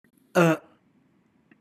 /ə/